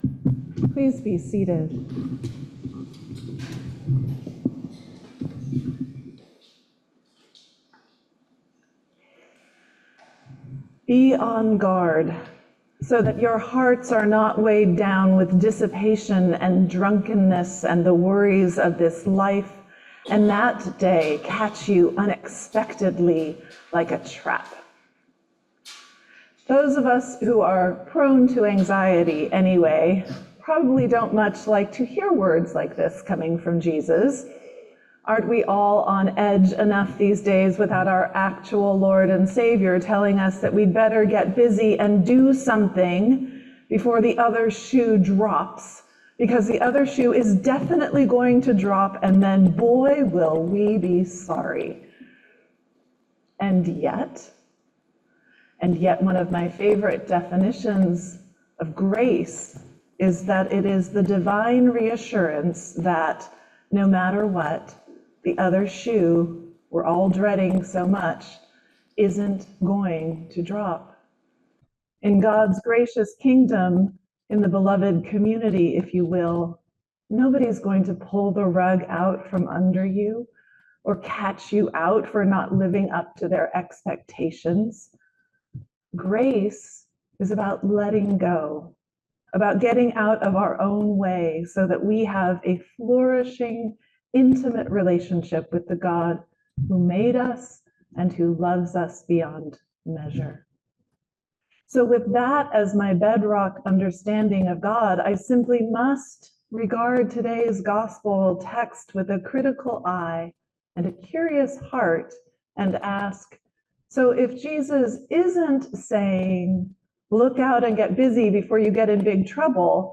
"Prophets & Presence": Sermon